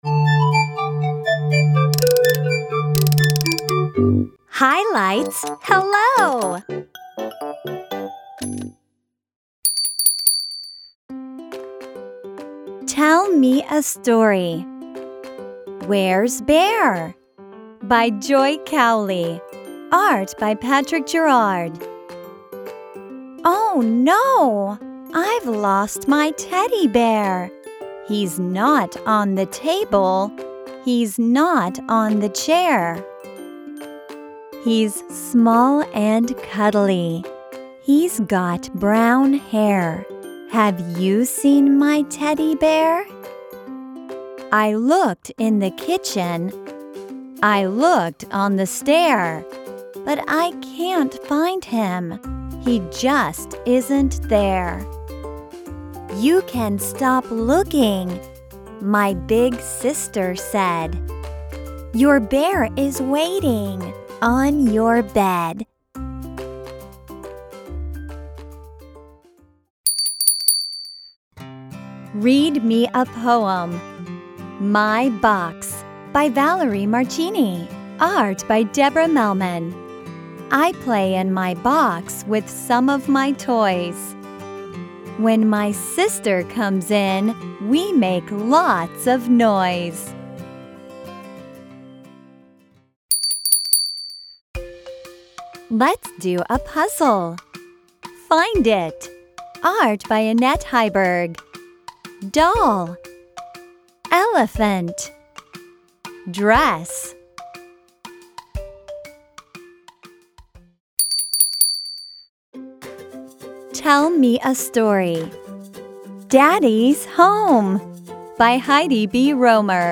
Below you will find audio narration of every book by a native English speaker.